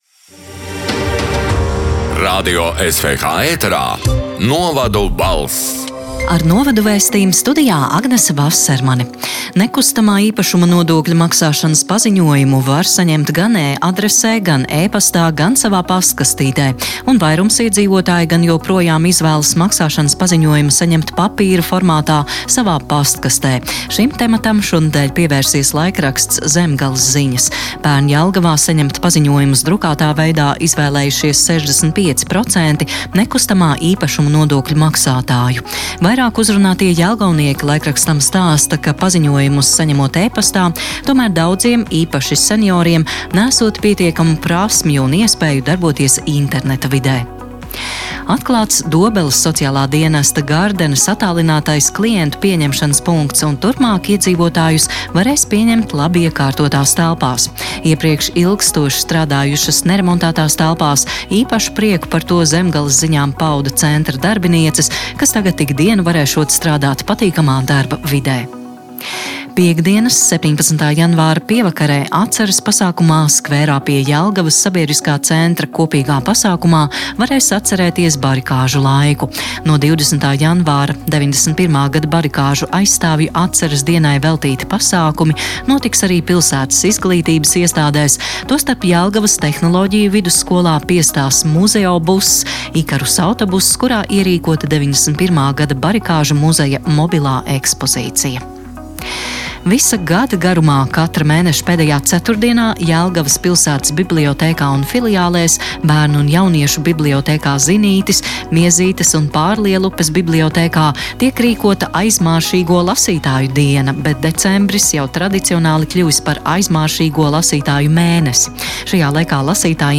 Radio SWH ēterā divreiz nedēļā izskan ziņu raidījums “Novadu balss”, kurā iekļautas Latvijas reģionālo mediju sagatavotās ziņas. Raidījumā Radio SWH ziņu dienests apkopo aktuālāko no laikrakstiem “Auseklis”, “Kurzemes Vārds”, “Zemgales Ziņas” un ”Vietējā Latgales Avīze”.
“Novadu balss” 17. janvāra ziņu raidījuma ieraksts: